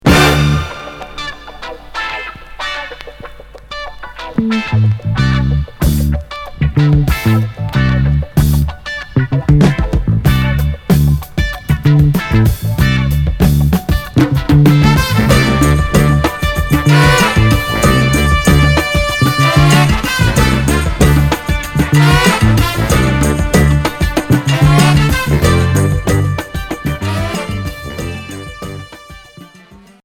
Groove Premier 45t